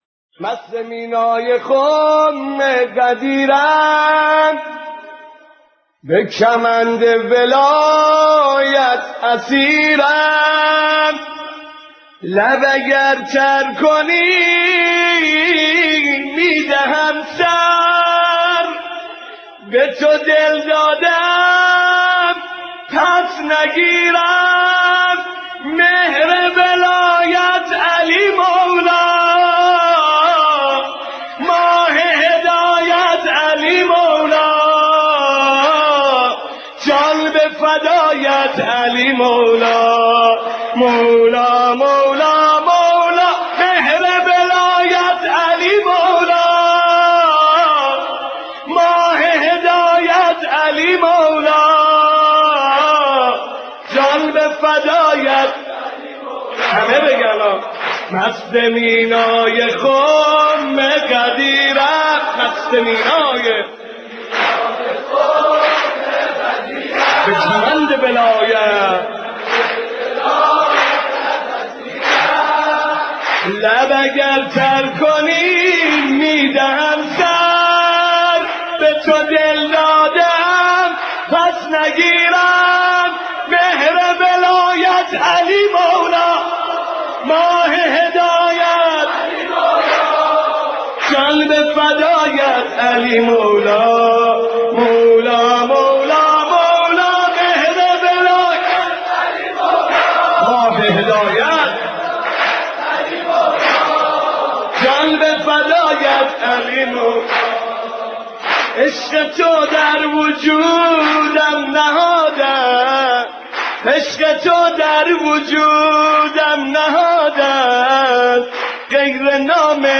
دانلود و پخش/ مولودی ویژه عید غدیر خم